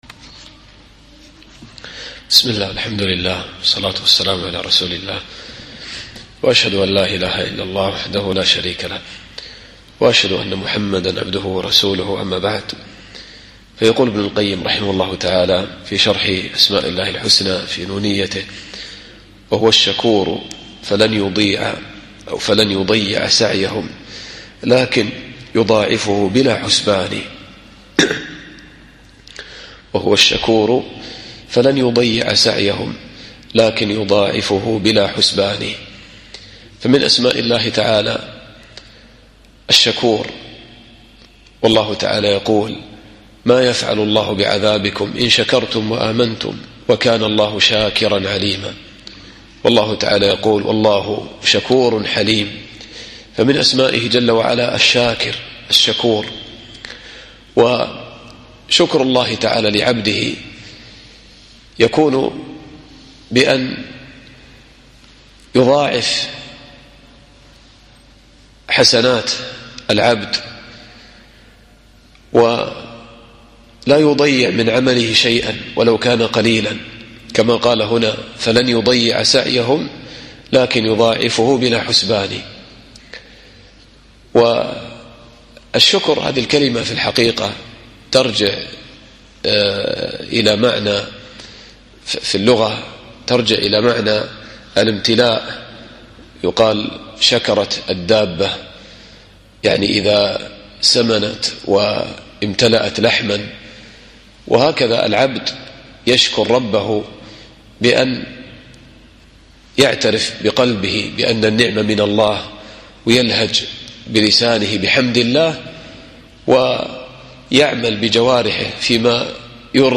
الدرس الثلاثون